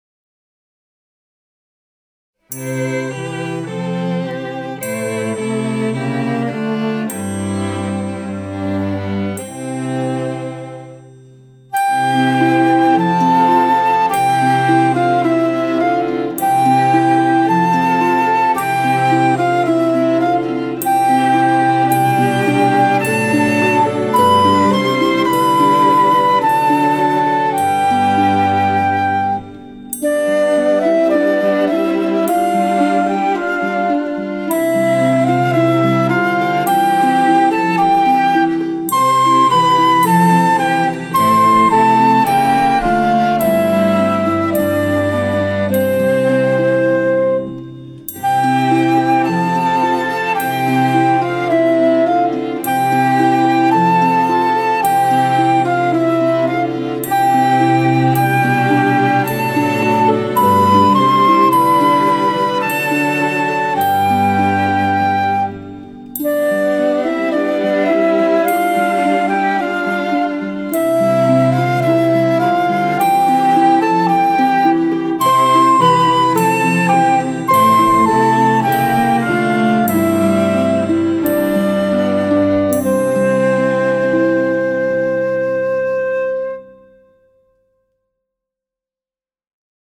Gattung: für Flöte und Klavier